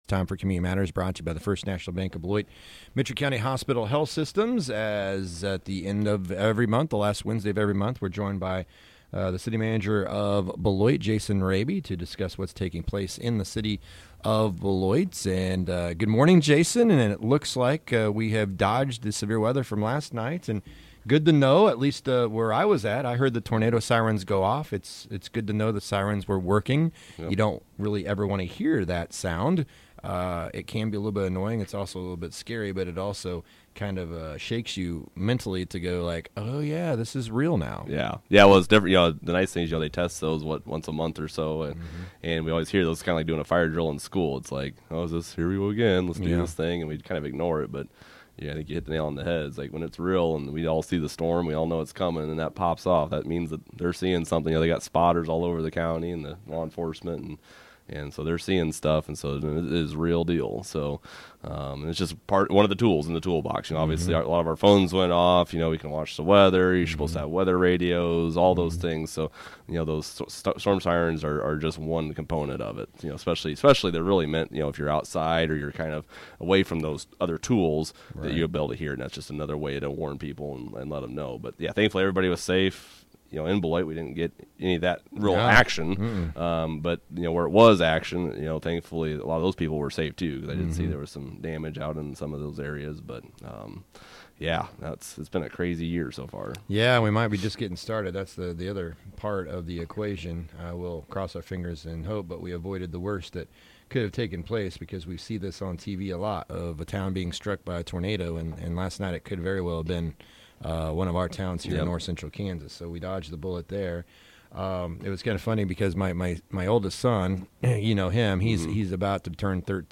Jason joins us to discuss the latest in regards to the city of Beloit